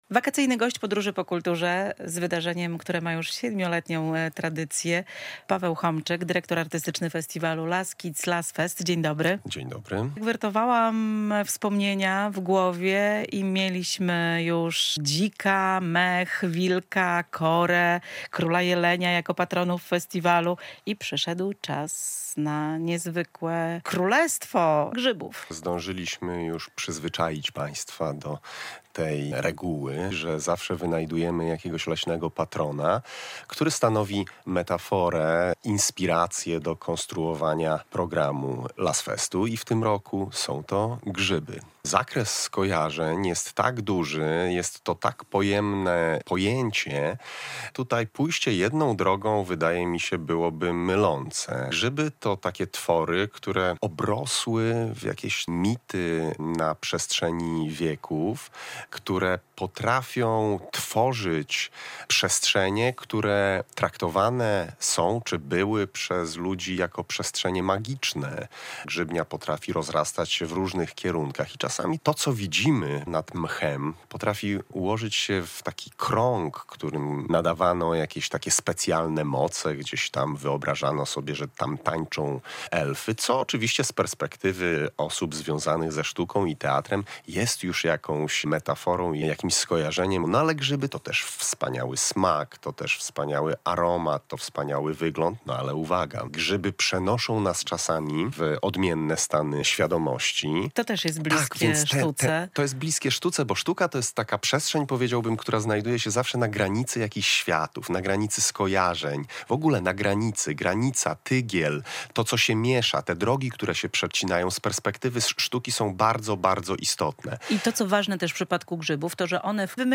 zaprosiła do studia Polskiego Radia Białystok